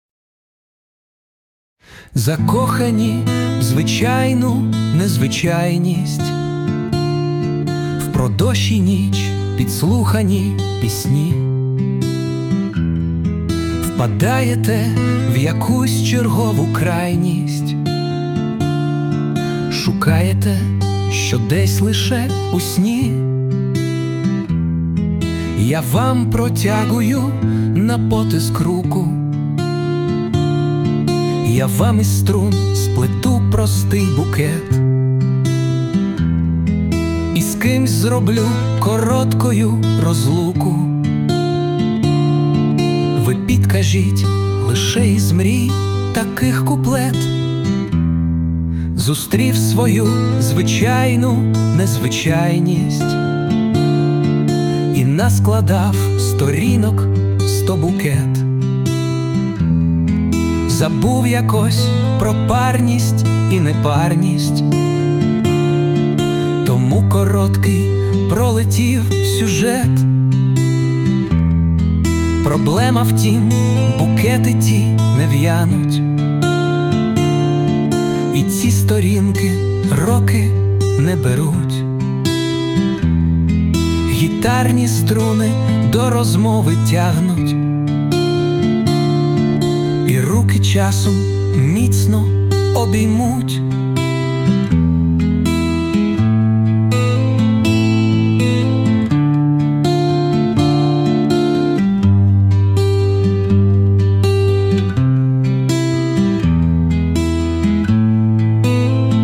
Власне виконання
Усунення перепадів звуку з допомогою SUNO.
СТИЛЬОВІ ЖАНРИ: Ліричний
Чудова пісня, гарне виконання!
Чистий звук, приємний голос...гітара...супер! hi 12 16